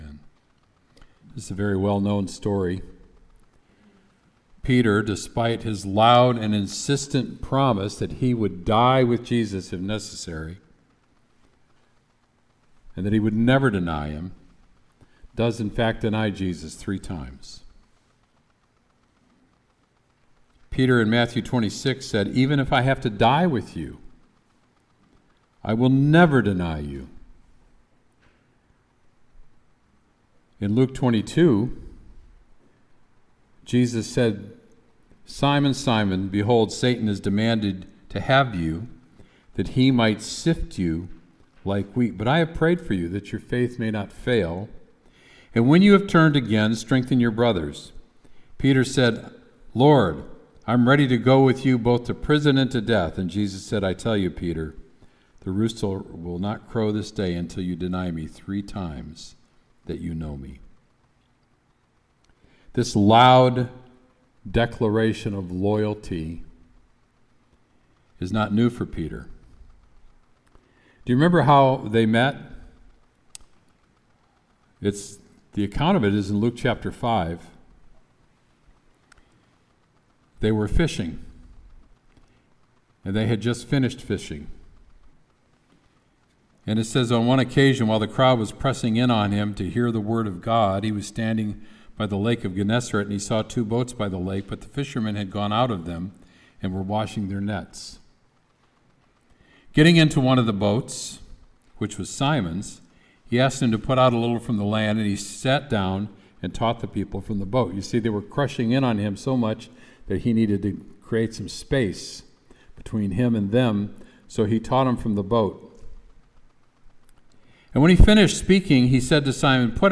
Sermon “Peter Denies Jesus”